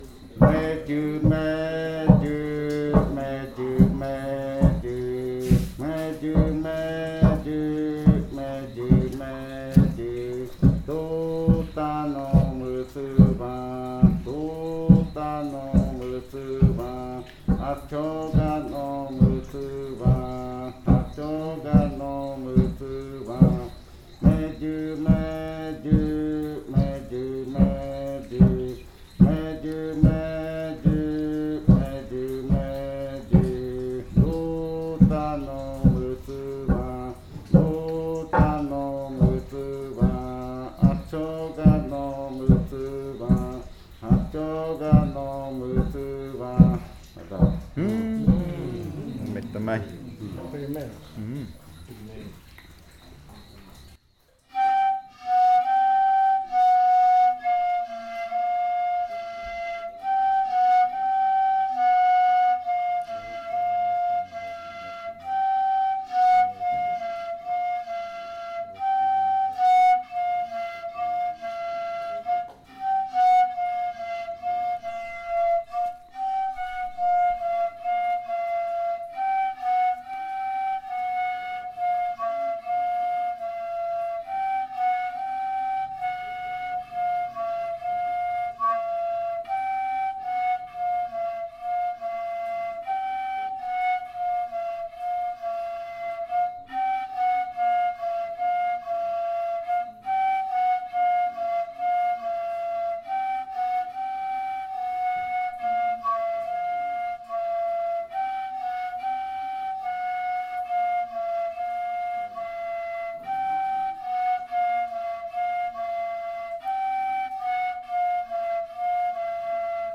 Leticia, Amazonas, (Colombia)
Canto Meyɨ meyɨ.'Libélula' (lengua muinane) e interpretación del canto en pares de reribakui.
'Dragonfly' (Múinane language) and performance of the chant in reribakui flutes.
This recording is part of a collection resulting from the Kaɨ Komuiya Uai (Leticia) dance group's own research on pan flutes and fakariya chants.